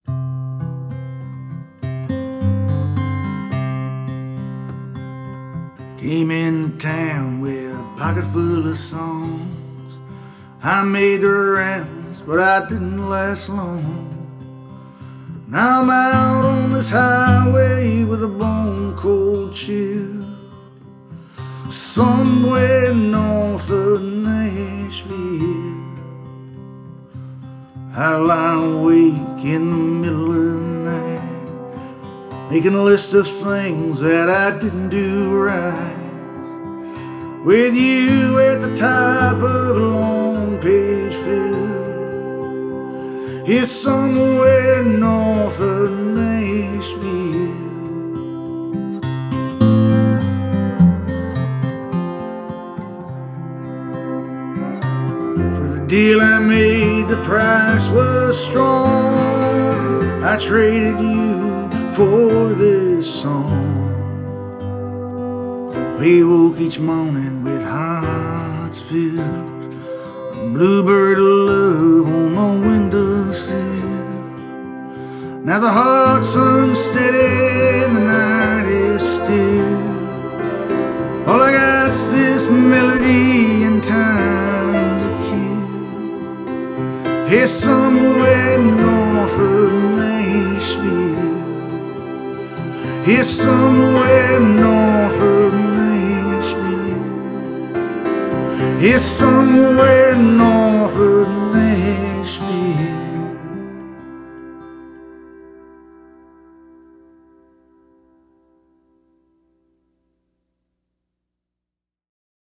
mournful